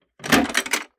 Ammo Crate Close 001.wav